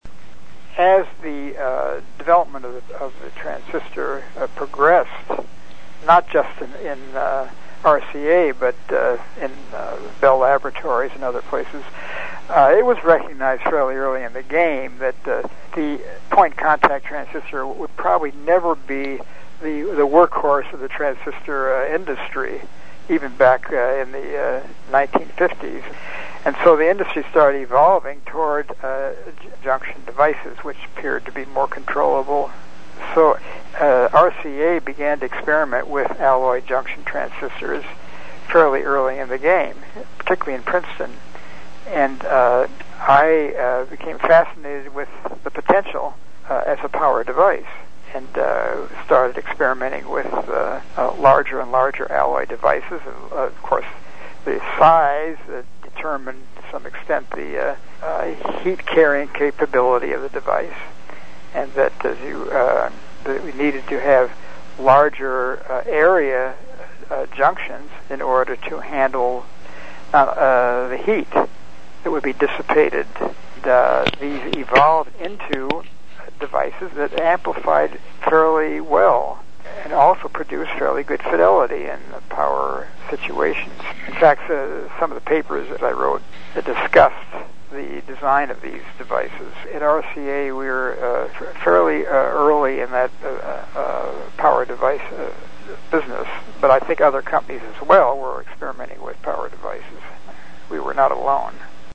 from a 2005 Interview with